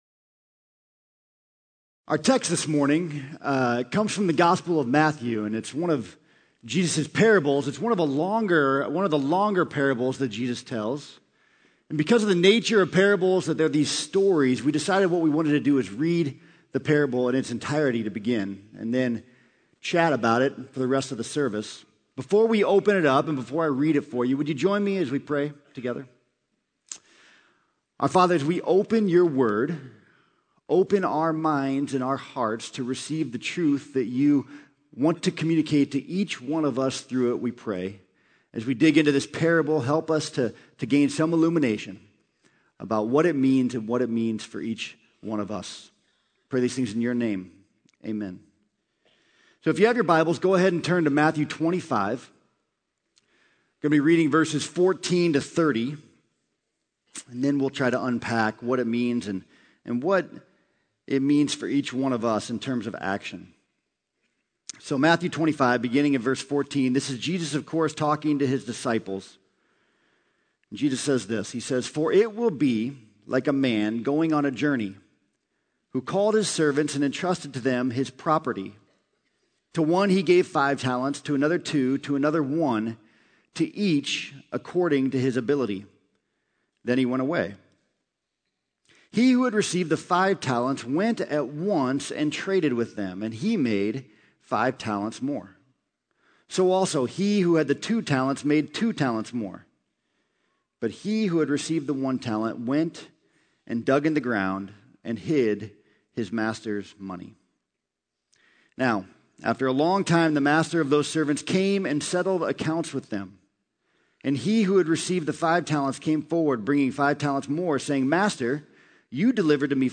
Sermon: Faithful Servants